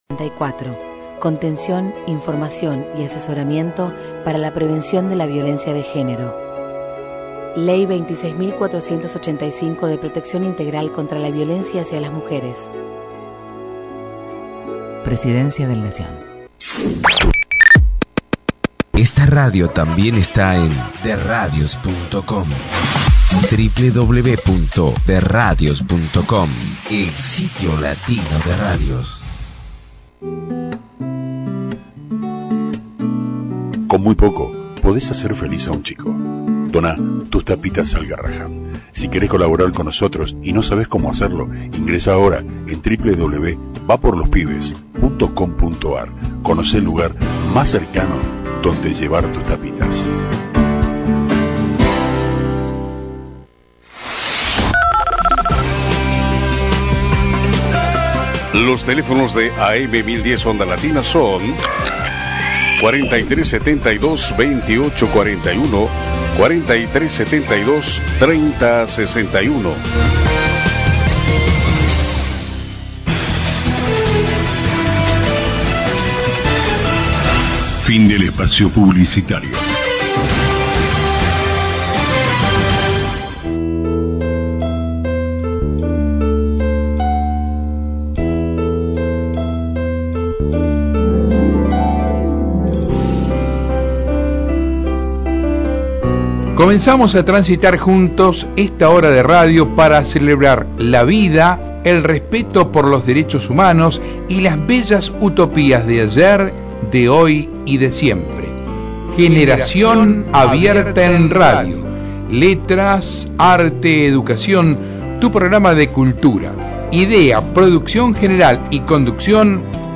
Diálogo en vivo